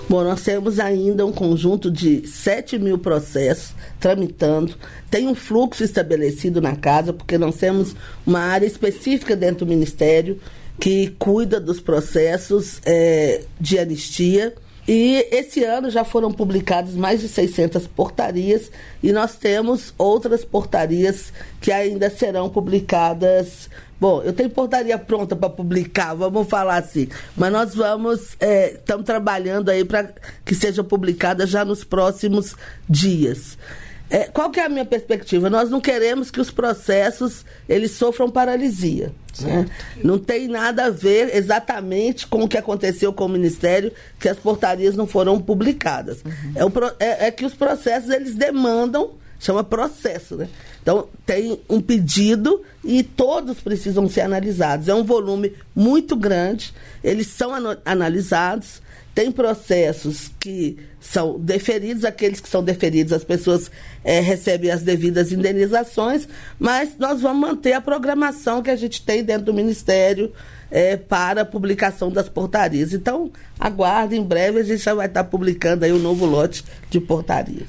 Trecho da participação da ministra dos Direitos Humanos e da Cidadania, Macaé Evaristo, no programa Bom Dia, Ministra desta quarta-feira (02), nos estúdios da EBC, em Brasília.